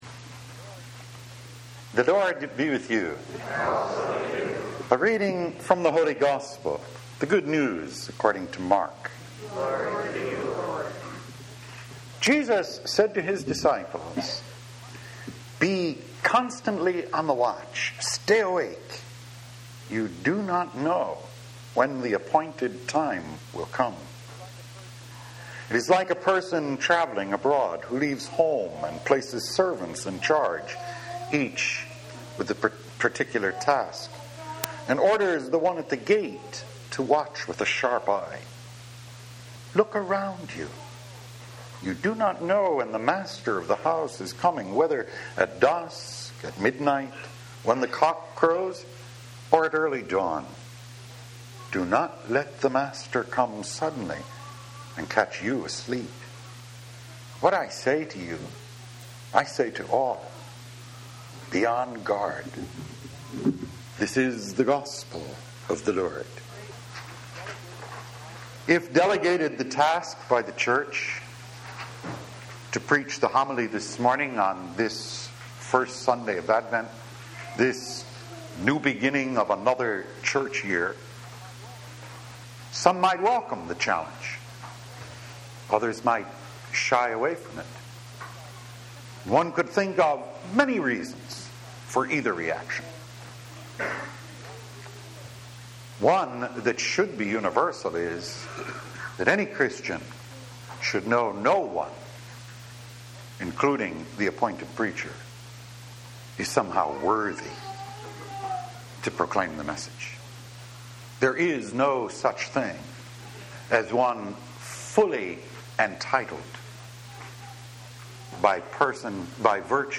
War – Weekly Homilies